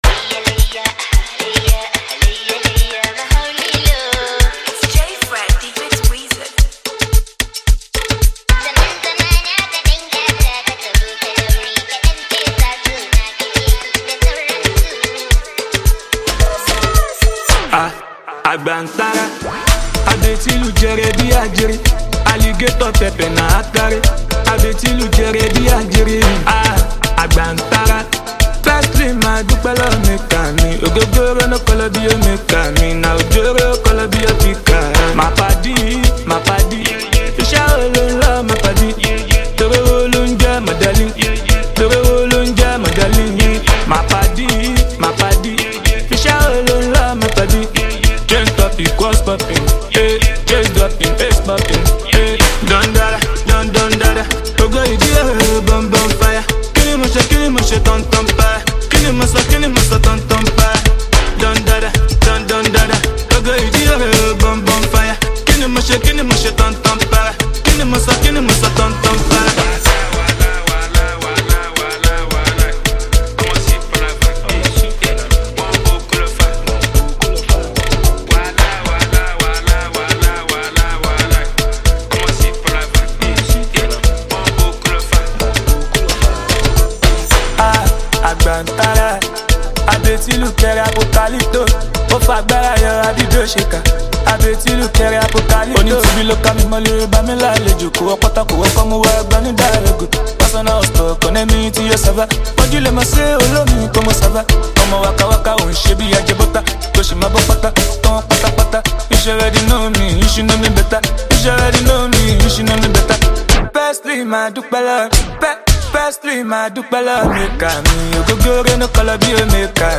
Nigeria talented Afrobeats singer and songwriter